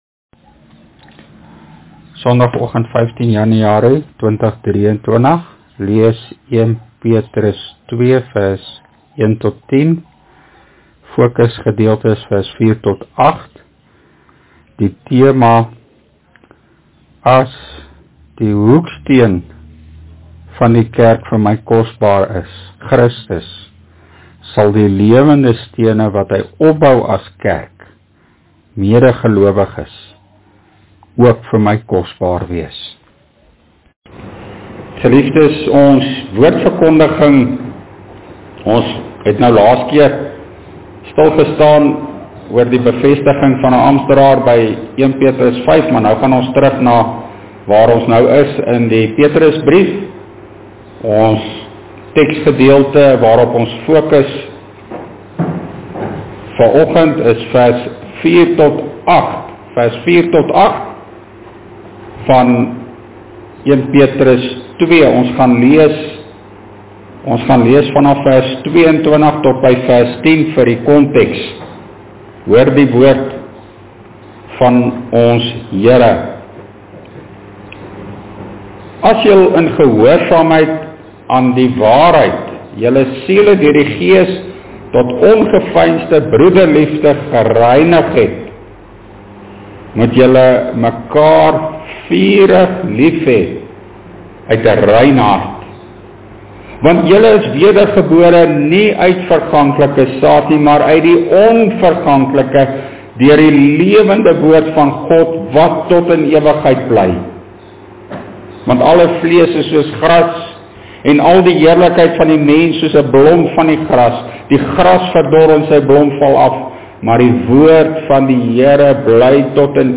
Opname (GK Carletonville, 2023-01-15)